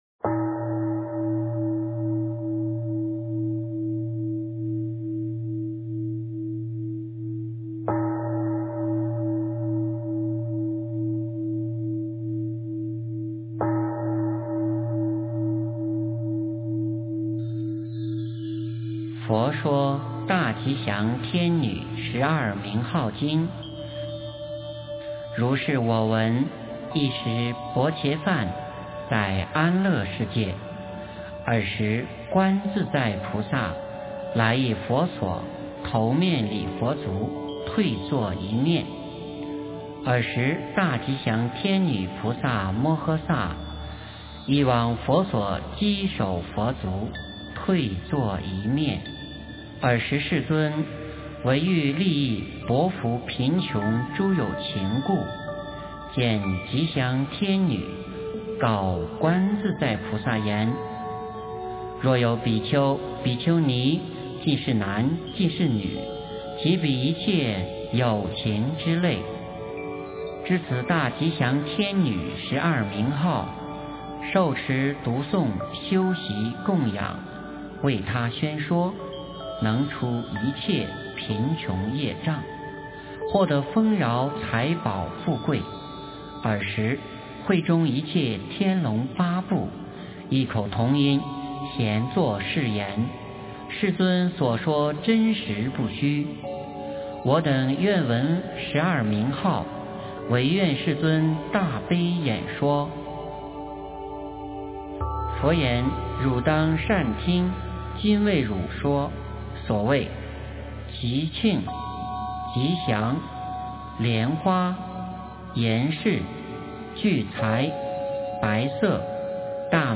佛说大吉祥天女十二名号经 - 诵经 - 云佛论坛